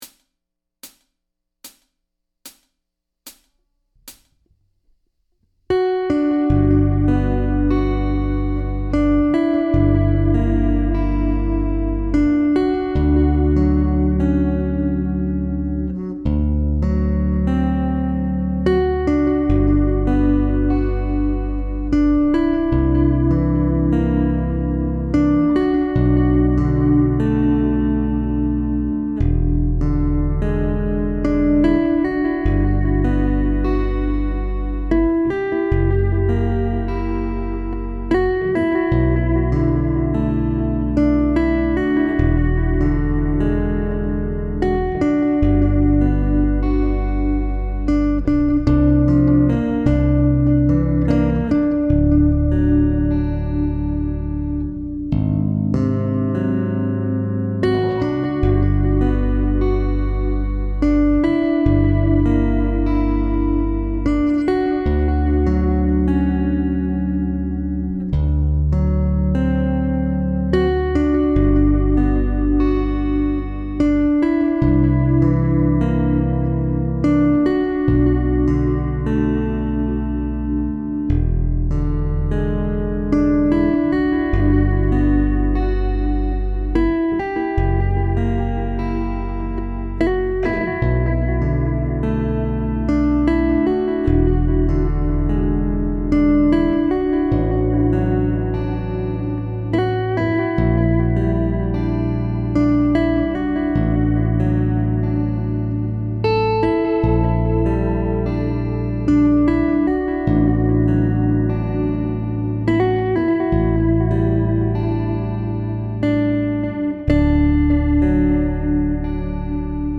• Instrumental
is a hypnotic ballad.
Based on the same bass riff throughout the piece.